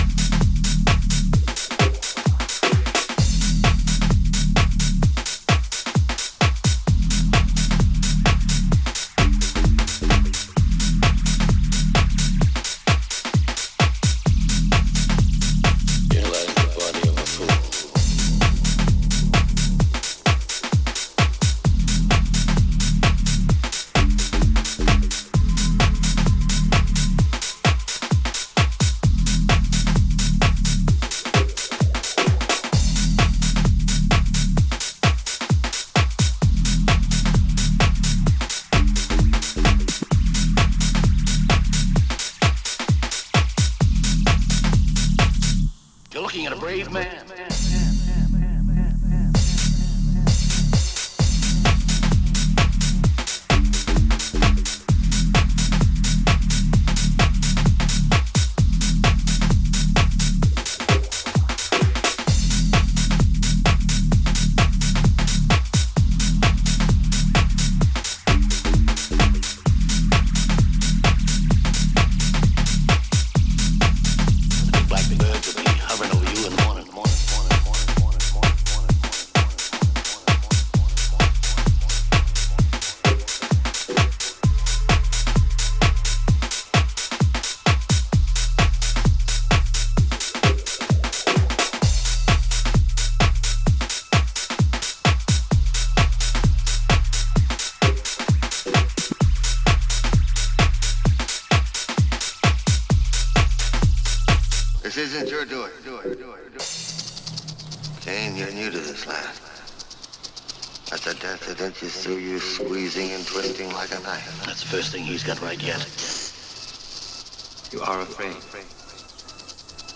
分厚くサブベースを鳴らしフロアを制圧する